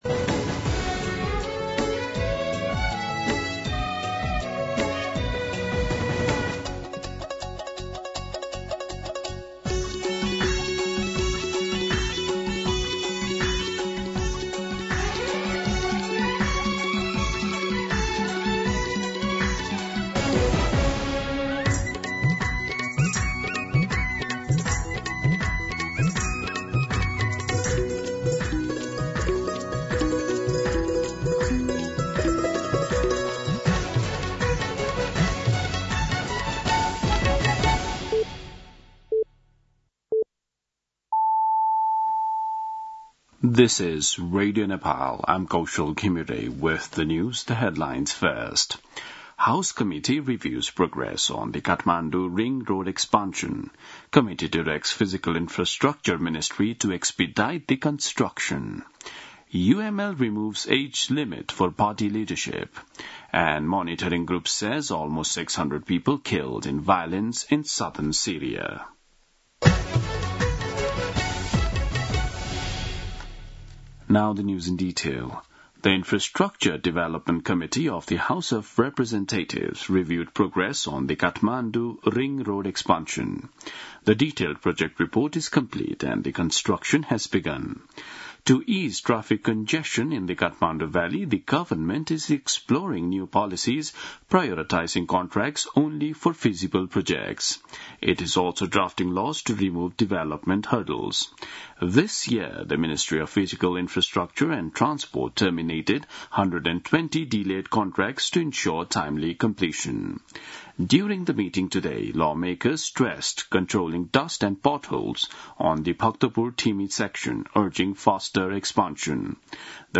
दिउँसो २ बजेको अङ्ग्रेजी समाचार : २ साउन , २०८२